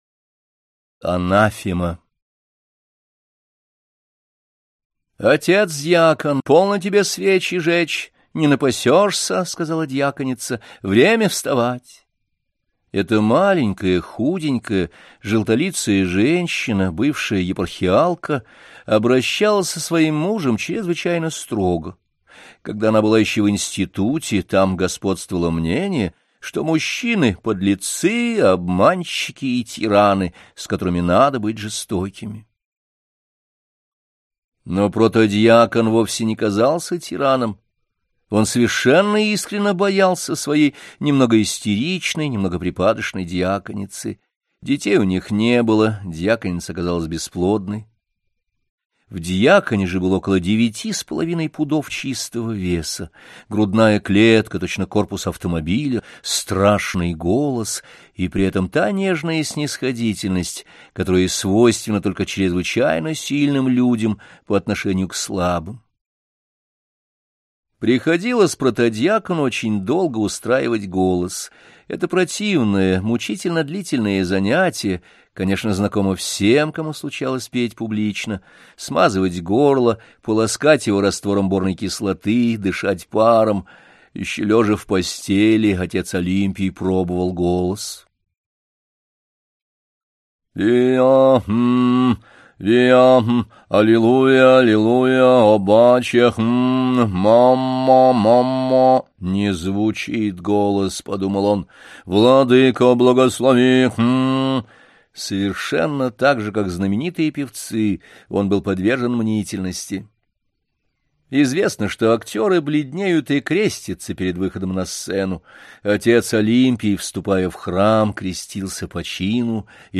Аудиокнига Повести и рассказы | Библиотека аудиокниг